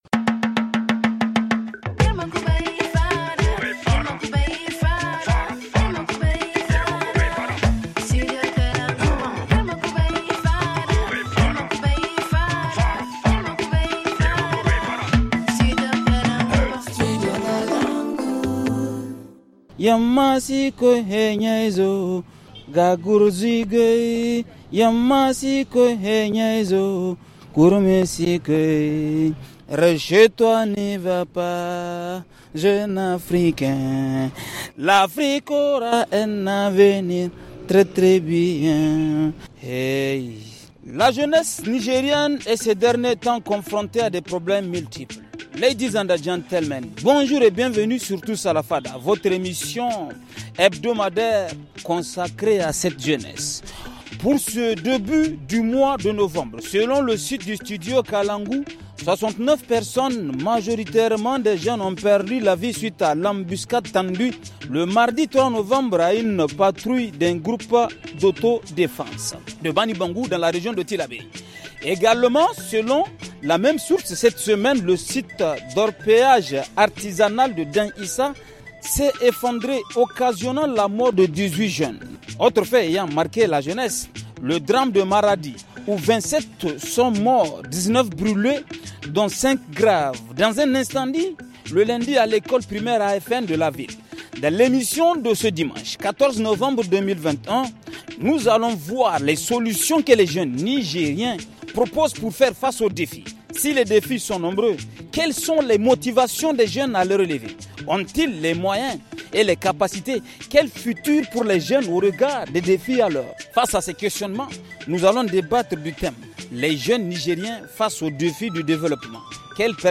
Nous sommes à la place publique située en face du commissariat central de Niamey avec nous dans la fada